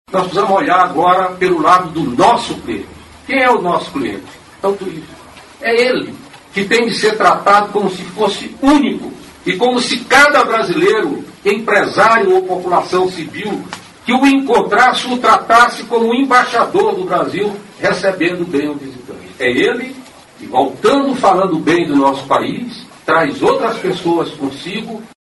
aqui e ouça fala do ministro Gastão Vieira sobre a importância do bom atendimento para a atração de visitantes.